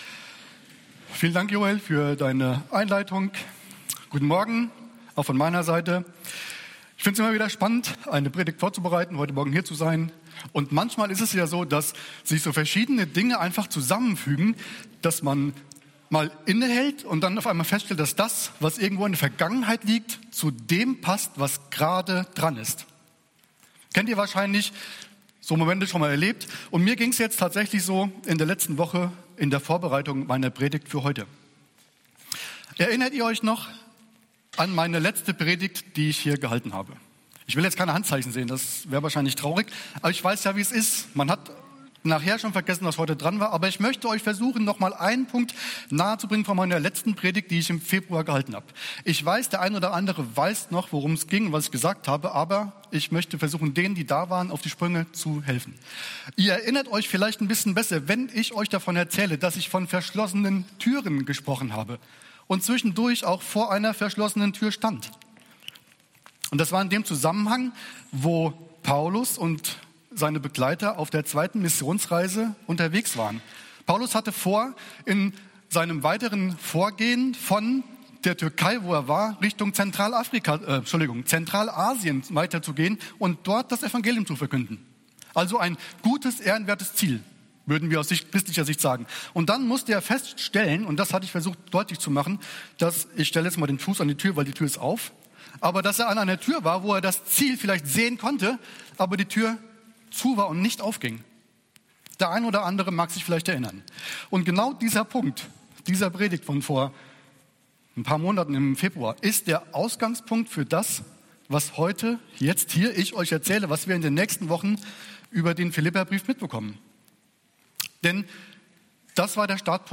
EFG-Haiger Predigt-Podcast